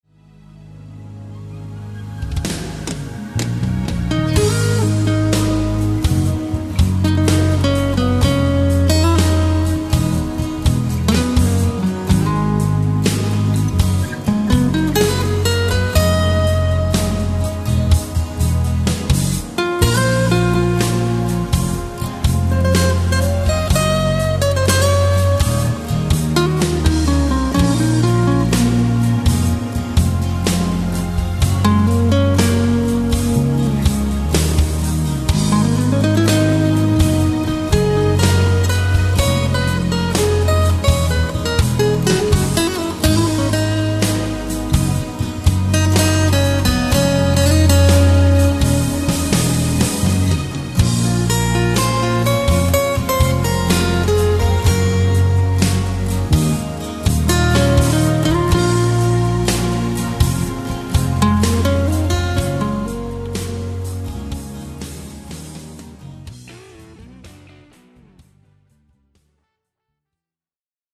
Progressive Rock